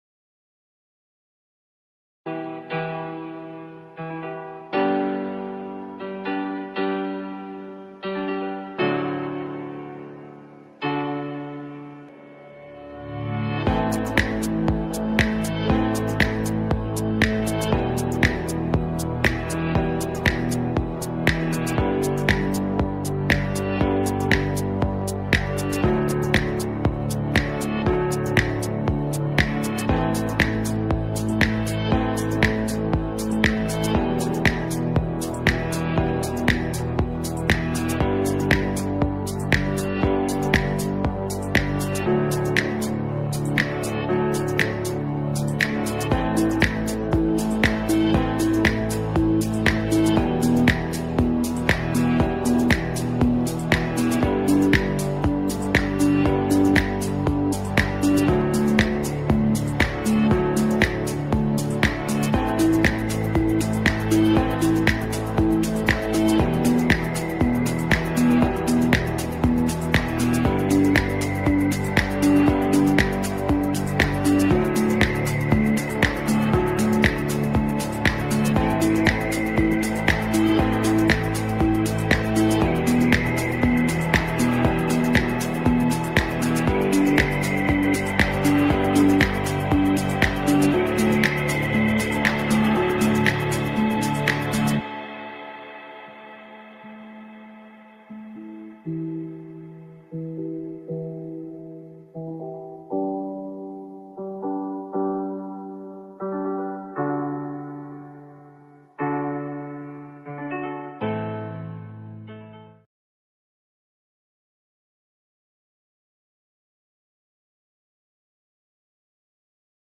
This is a fast-paced podcast that ultimately leaves you with a coherent and actionable trade plan, identifying our trade selections for the day, what direction we are looking to trade them and the prices we are looking to engage. This daily podcast could become an important part of your trading toolkit and is broadcast live to our traders every morning.